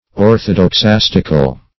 Orthodoxastical \Or`tho*dox*as"tic*al\, a.
orthodoxastical.mp3